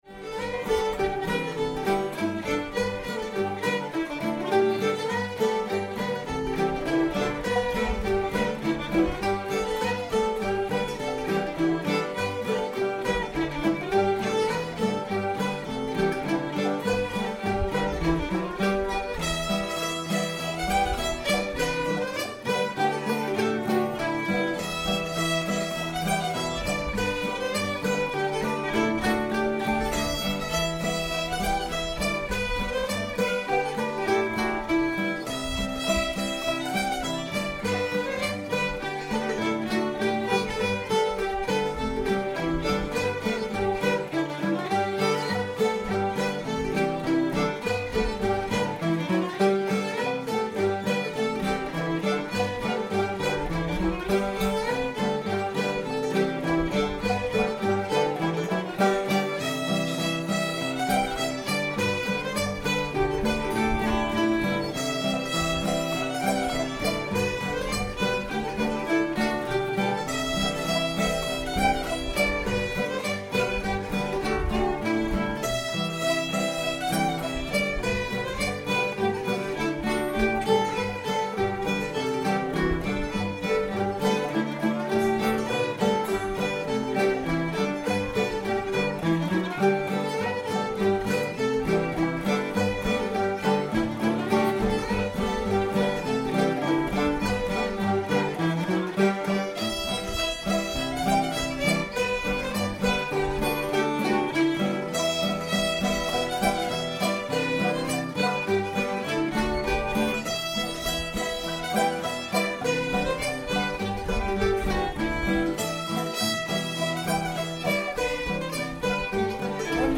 old aunt jenny with her nightcap on [G]